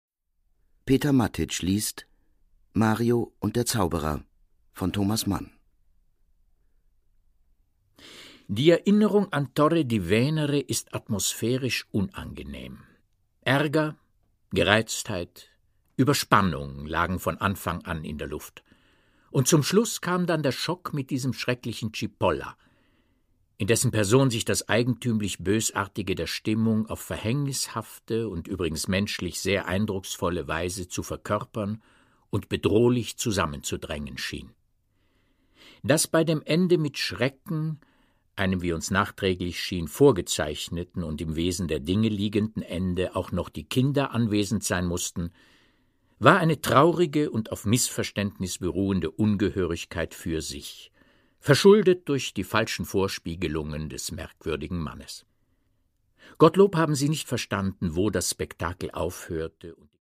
Produkttyp: Hörbuch-Download
Gelesen von: Peter Matić